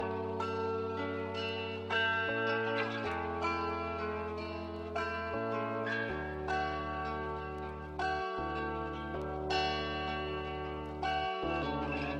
NO TELLING BPM 157.wav